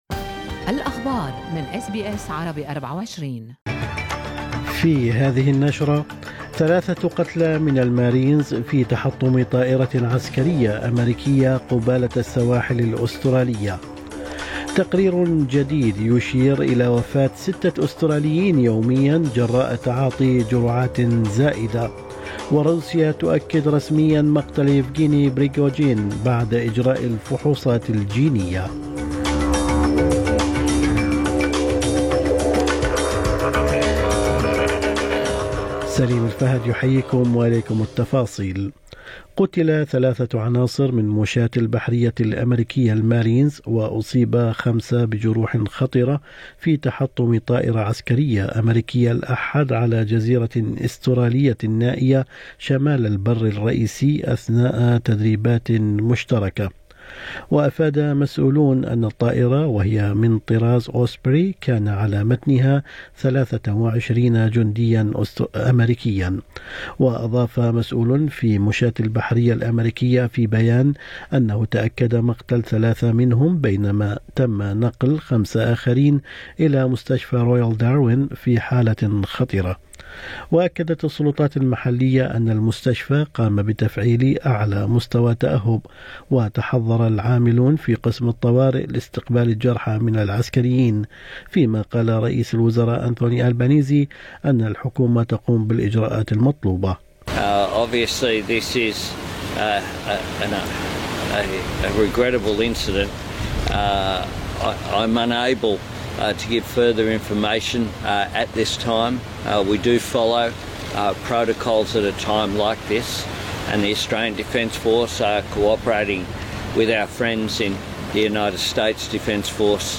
نشرة أخبار الصباح 28/8/2023